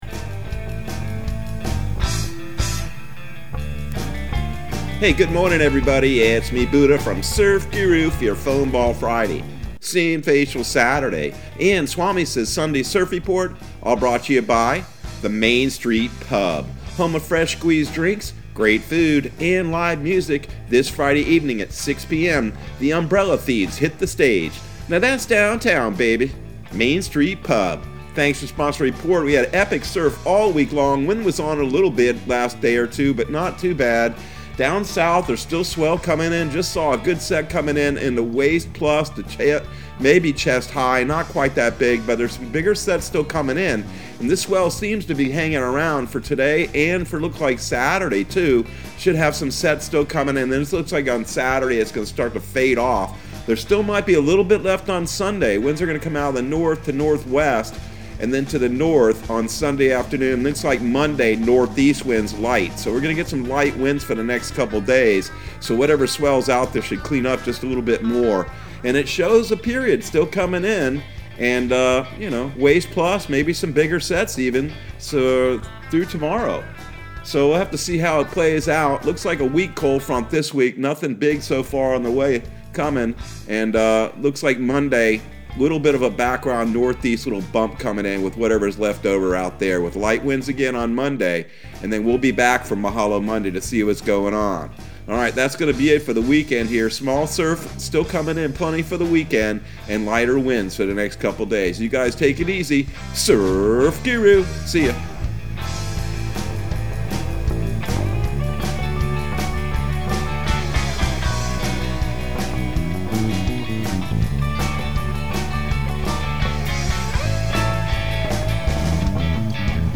Surf Guru Surf Report and Forecast 11/12/2021 Audio surf report and surf forecast on November 12 for Central Florida and the Southeast.